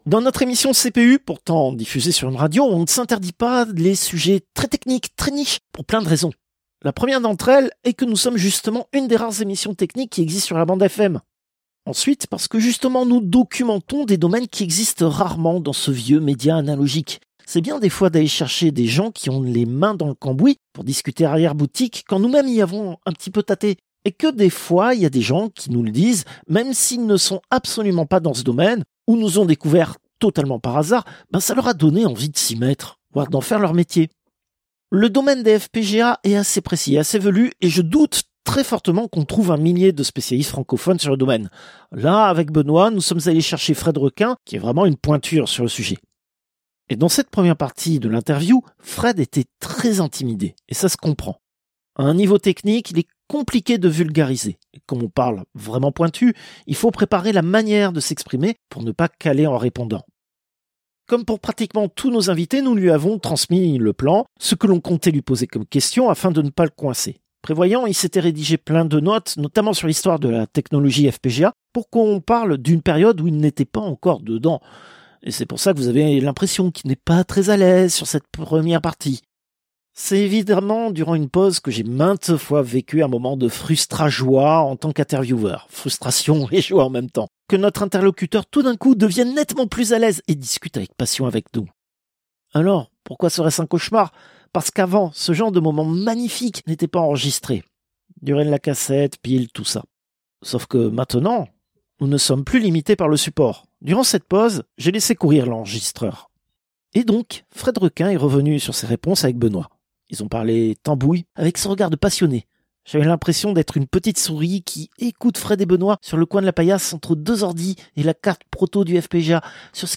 Extrait de l'émission CPU release Ex0234 : FPGA, troisième partie.